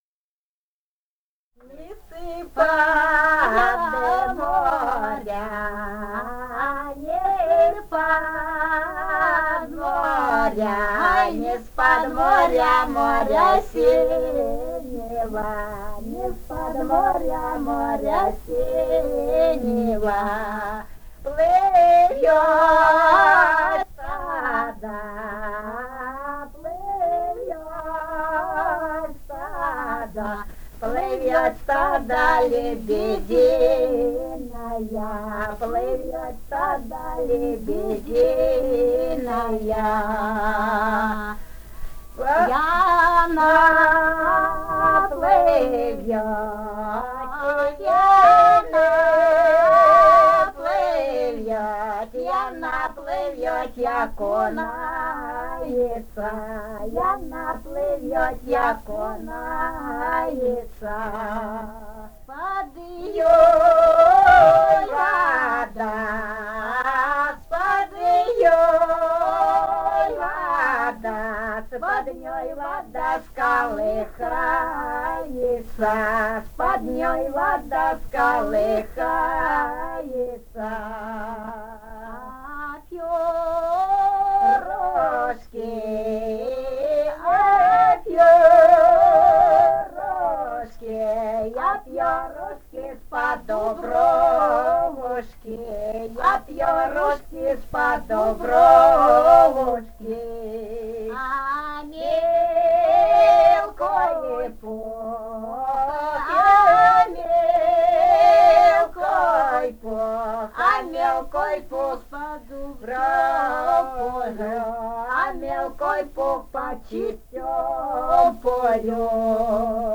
Этномузыкологические исследования и полевые материалы
«Ни с-под моря синего» (хороводная).
Румыния, с. Переправа, 1967 г. И0973-08